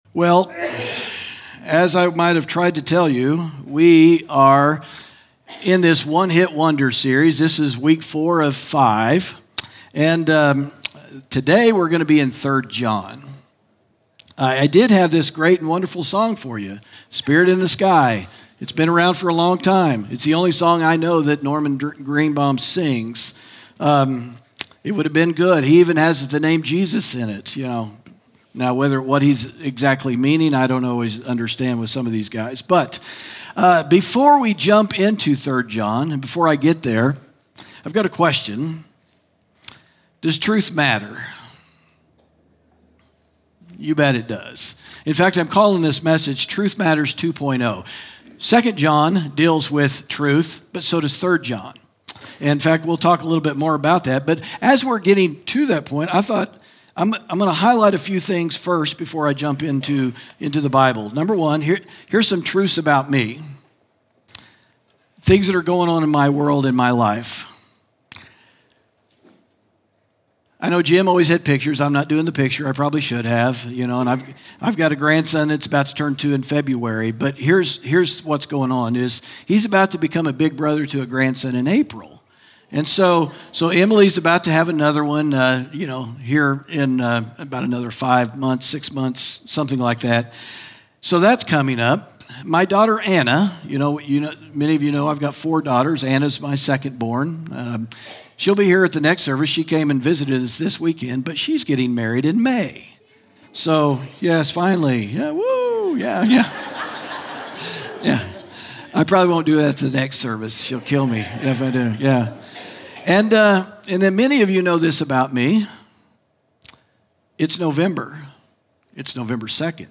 sermon audio 1102.mp3